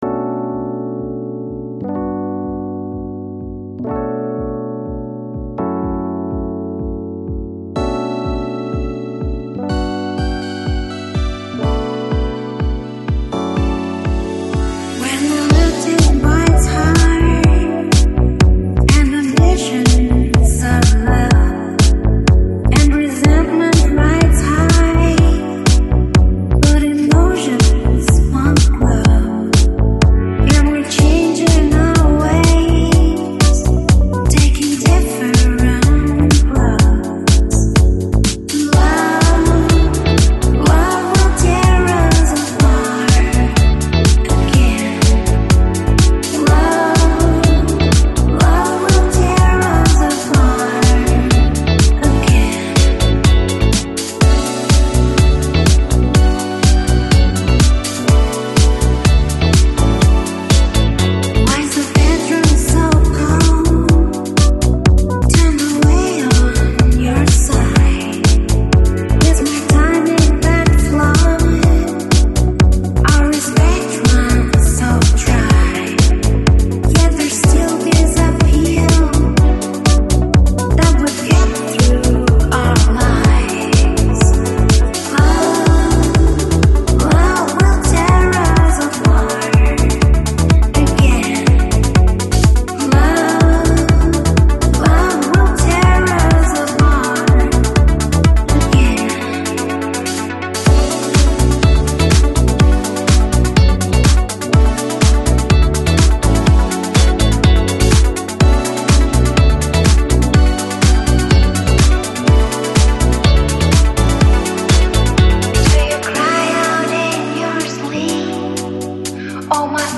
Жанр: Electronic, Lounge, Chill Out, Deep House